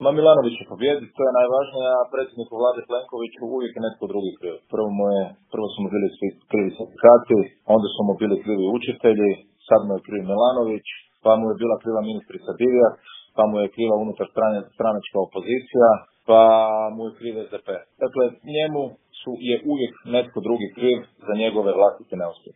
Davor Bernardić u intervjuu Media servisa o aktualnoj predsjedničkoj kampanji